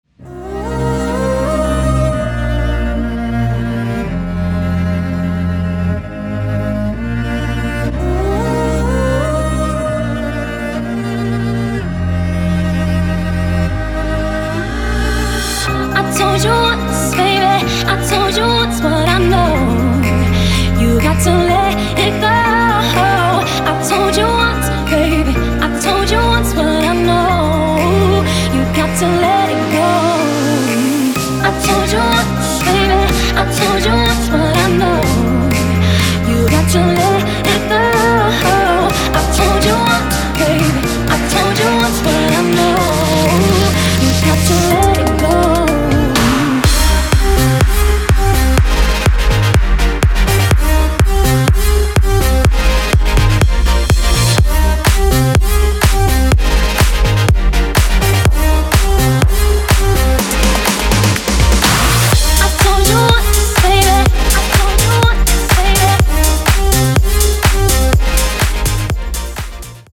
• Качество: 320, Stereo
женский вокал
EDM
электронная музыка
клавишные
скрипка
струнные
progressive house
Trance